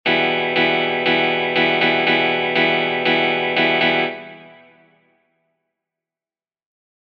in dieser Schlagmuster-Lesen-Übung, konzentrieren wir uns auf das Spielen von drei Viertelnoten gefolgt von zwei Achtelnoten.
Schlagmuster Gitarre Übungen